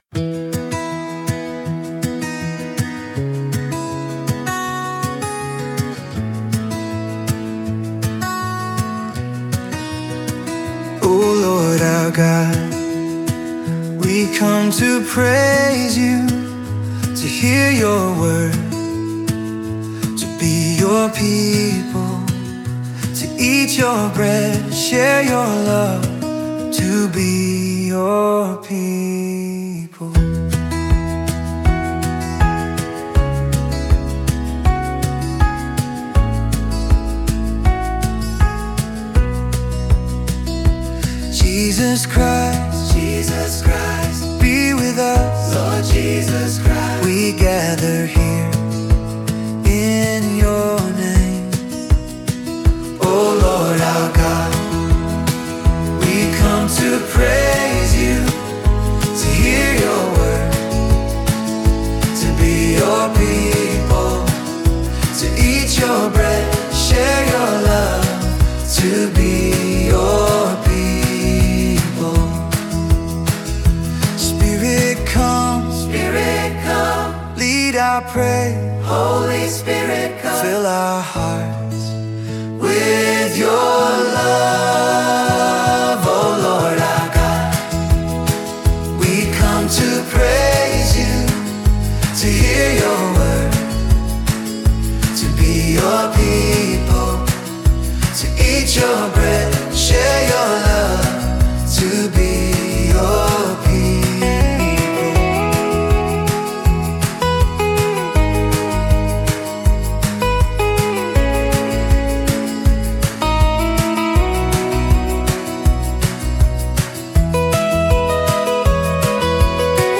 Mainstream, gathering song